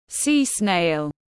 Sea snail /siː sneɪl/